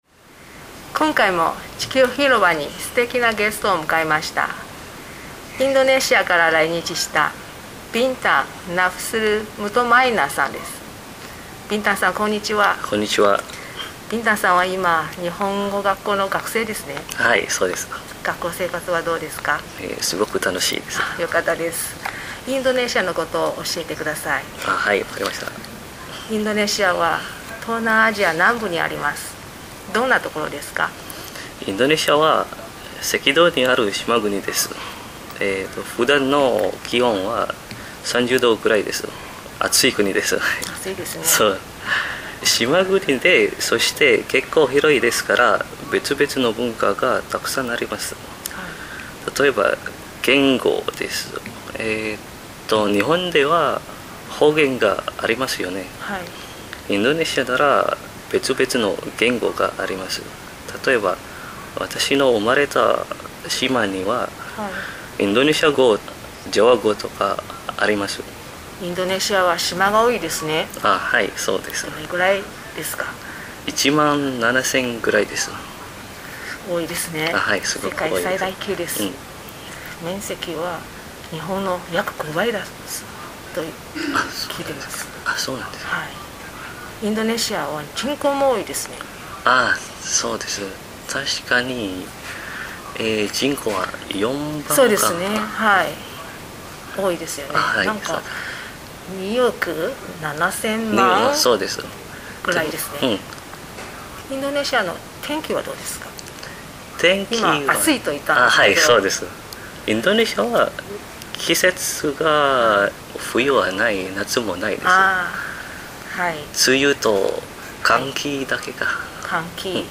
2024年7月～9月インタビュー
interview2407.mp3